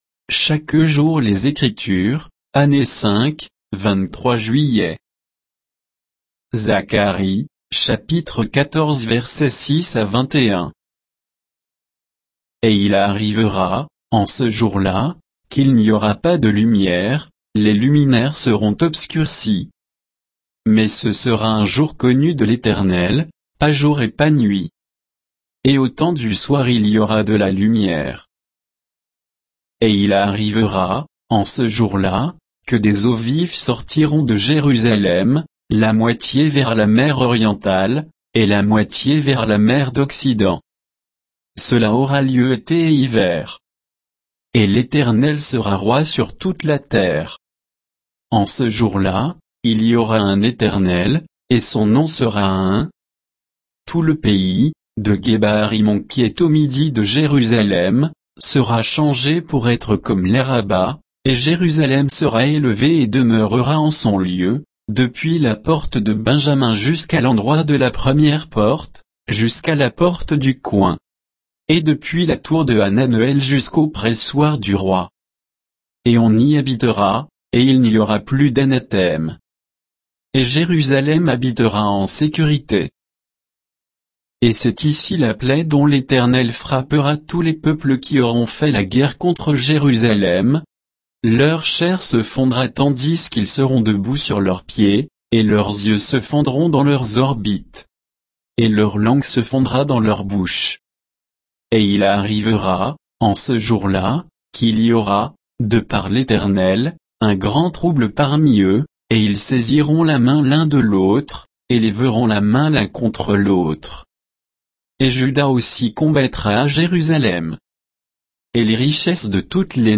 Méditation quoditienne de Chaque jour les Écritures sur Zacharie 14, 6 à 21